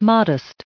773_modest.ogg